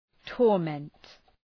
{tɔ:r’ment}